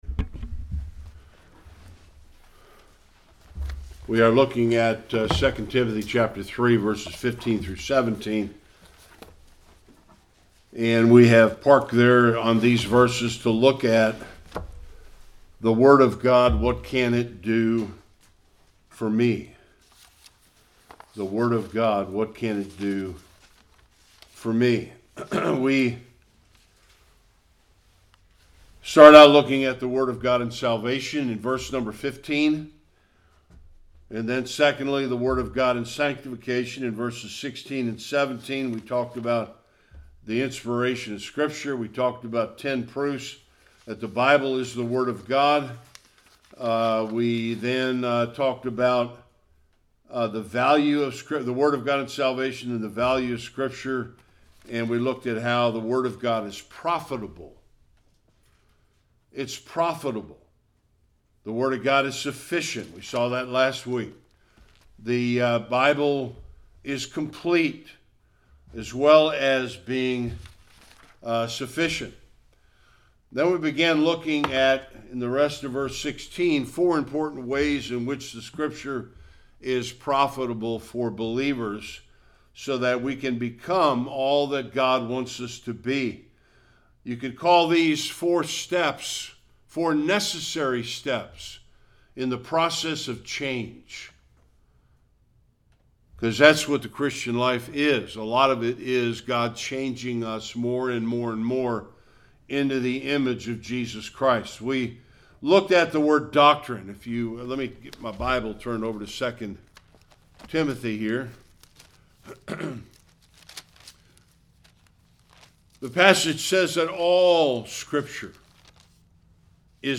15-17 Service Type: Sunday Worship The 2nd and third ways in which Scripture is profitable for believers.